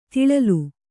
♪ tiḷalu